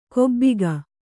♪ kobbiga